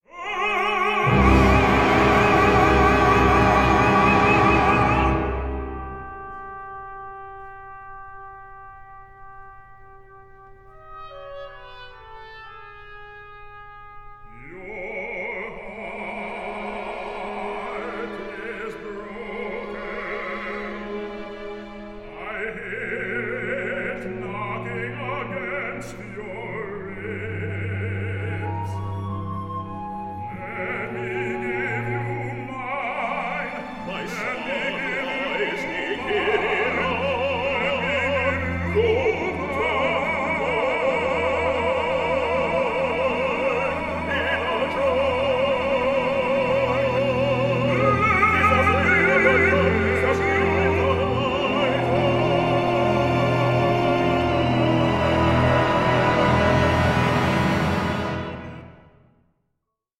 a contemporary opera on racial injustice in the US today